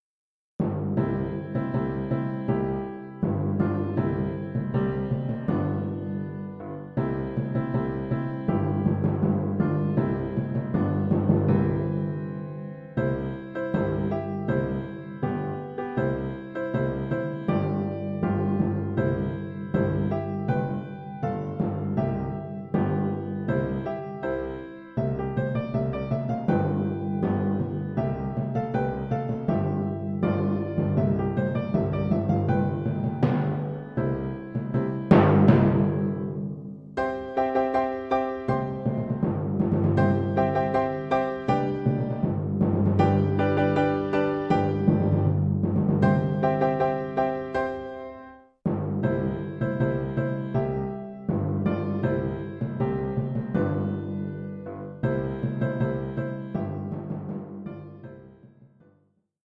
Oeuvre pour timbales et piano.